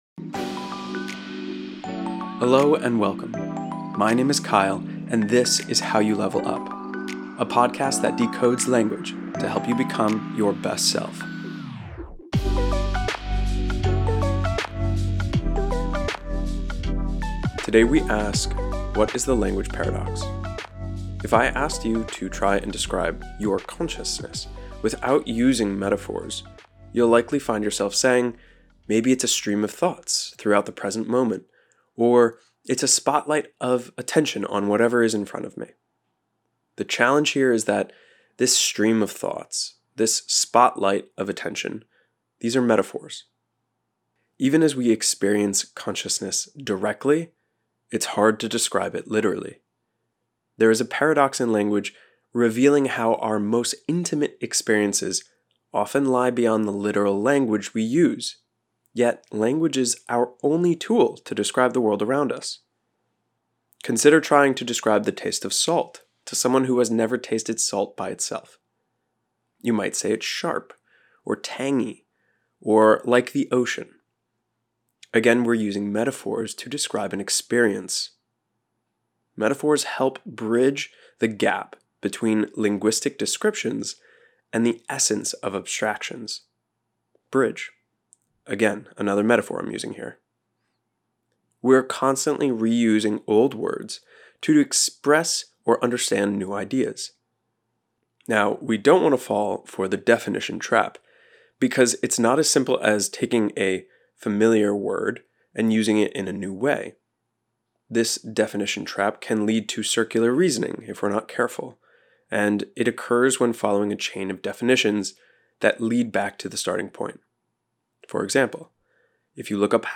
Intro/outro music: Fly Away by Mountaineer